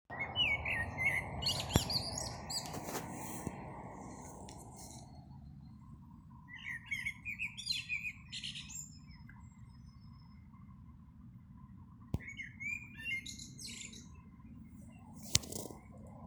черный дрозд, Turdus merula
Ziņotāja saglabāts vietas nosaukumsLantes muiža, Ropažu nov.
СтатусПоёт